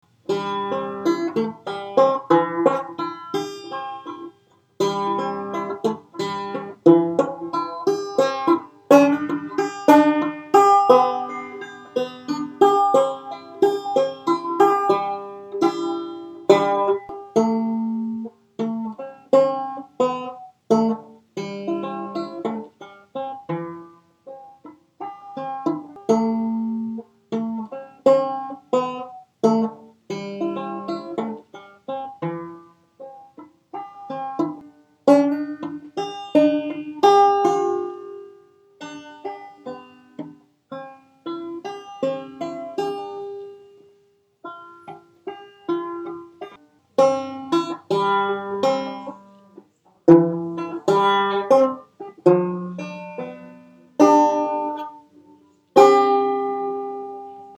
"Silent Night, Holy Night" is a staple hymn for candlelight services.
three-finger or Scruggs-style
banjo